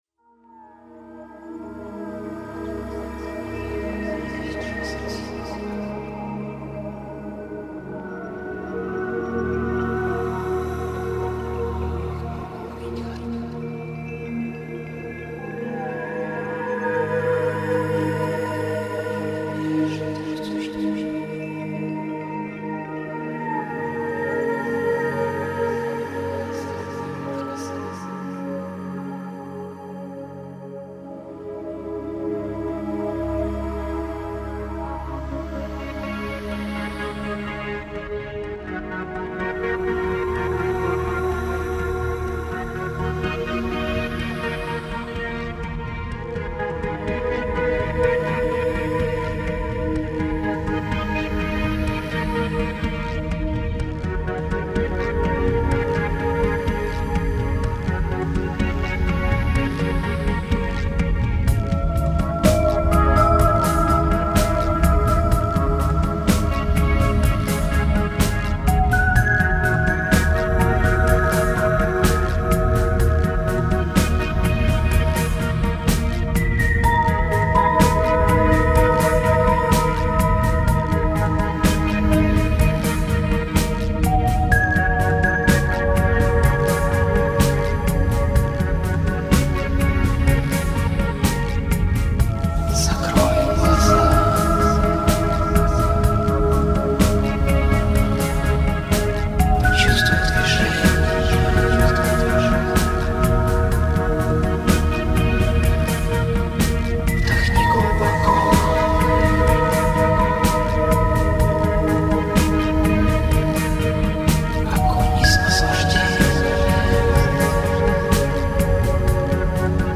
Genre: Electronic.